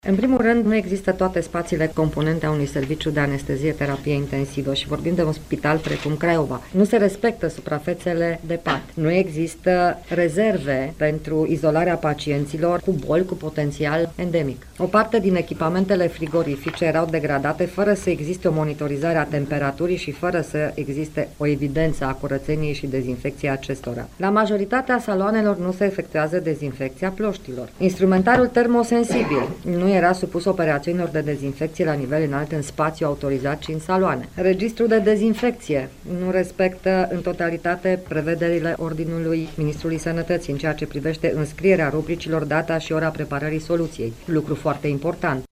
În urma acestor controale s-au aplicat aproape 6.000 de amenzi, după cum a declarat în cadrul unei conferinţe de presă în care a prezentat bilanţul activităţii în anul 2018, Ministrul Sănătăţii, Sorina Pintea: